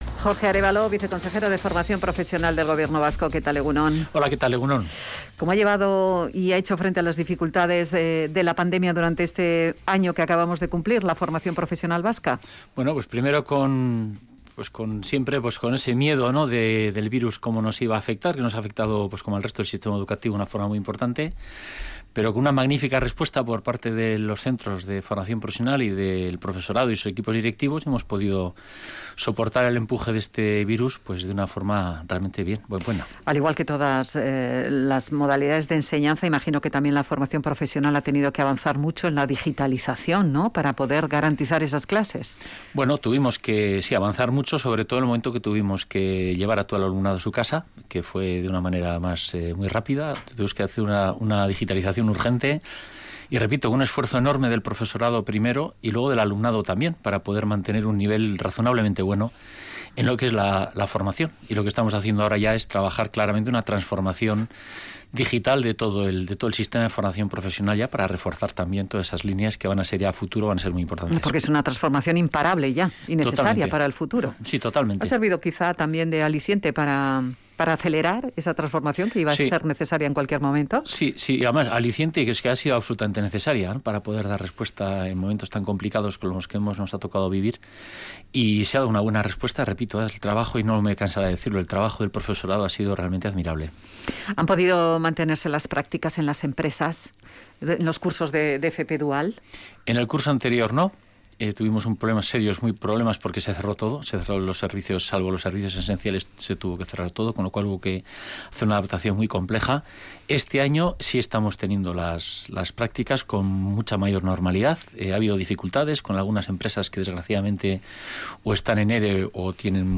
Audio: Jorge Arévalo, viceconsejero de Formación Profesional, ha reconocido en Radio Vitoria que la situación de dificultad en las empresas ha supuesto un descenso